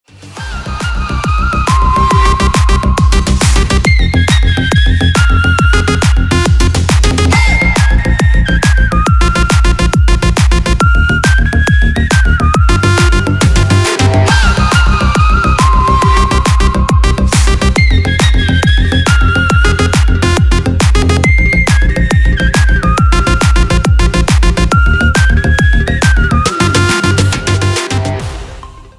• Качество: 320, Stereo
мощные
Electronic
без слов
энергичные
psy-trance
Стиль: trance / psy trance